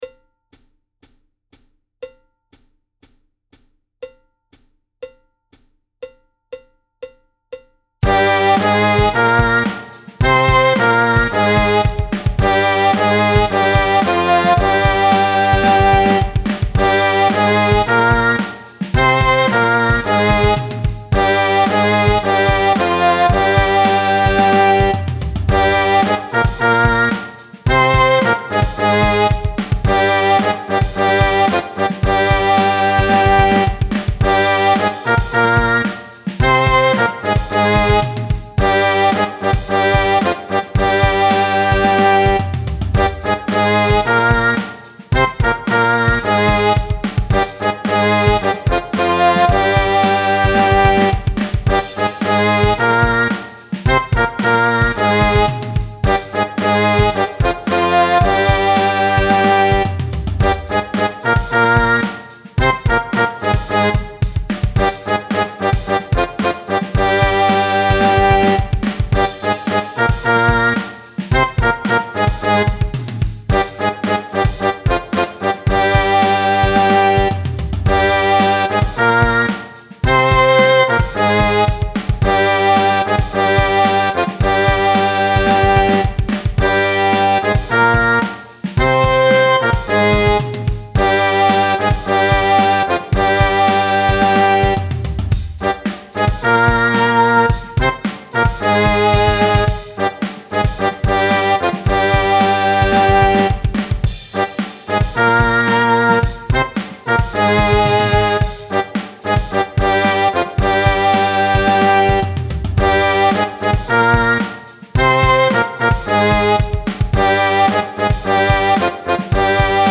– die Stimmung ist 440Hertz
– der Einzähler des Playbacks beginnt 4 Takte vor dem Song
– das Playback ist aktuell noch eine Midi-Version
Zweistimmige Übung - Tempo 110
Nr. 1 bis 7 | nur 1. Stimme